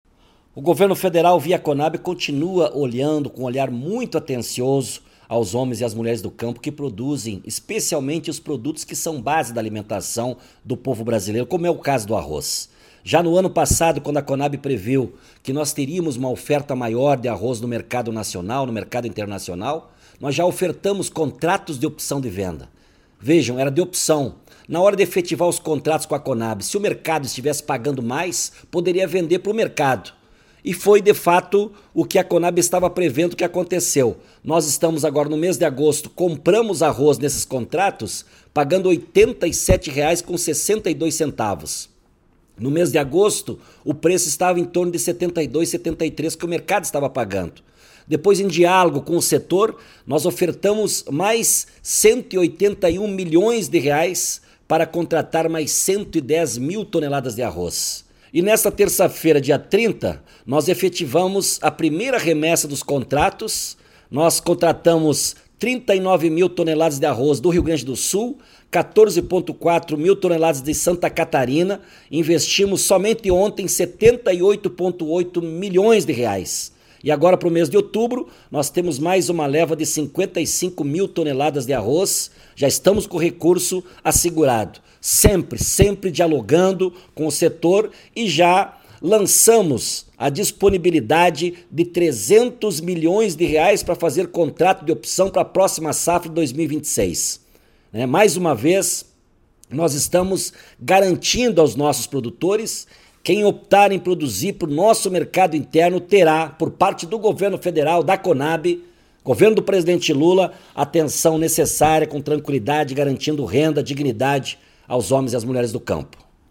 Você pode escutar a fala de Edegar Pretto aqui:
COV-Especial-Edegar-Pretto-presidente-da-Conab.mp3